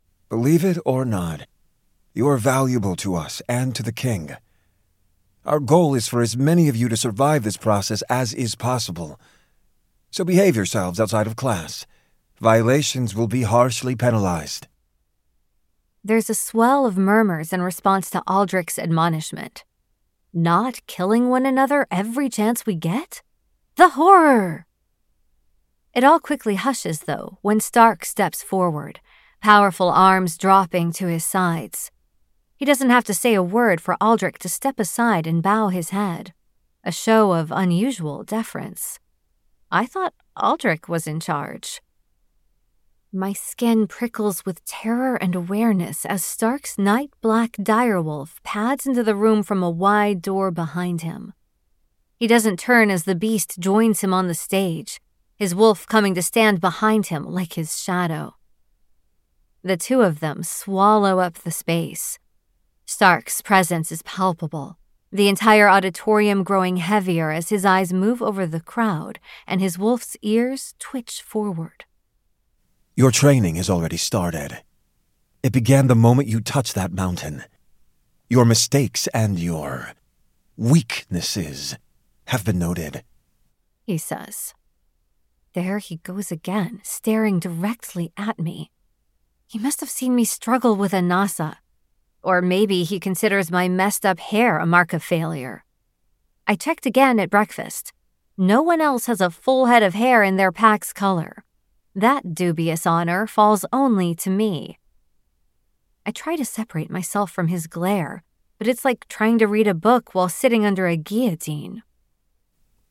1. Audiobook Download (Unabridged) $44.99